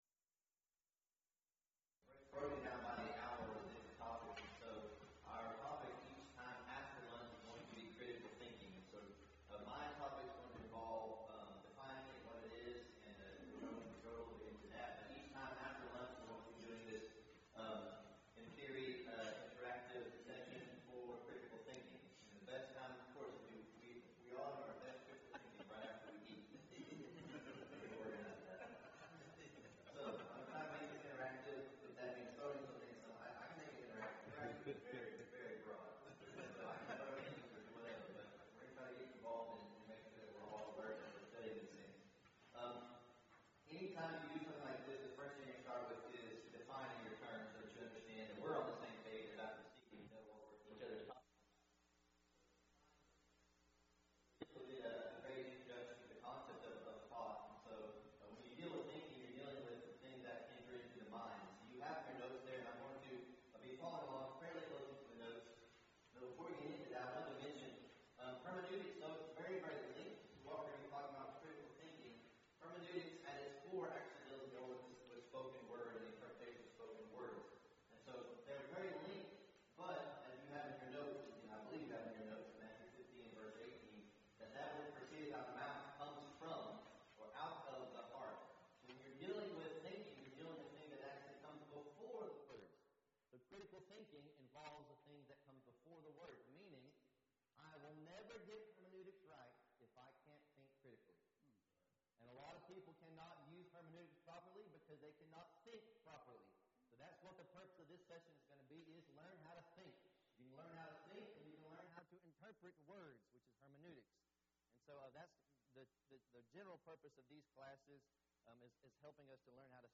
Event: 4th Annual Men's Development Conference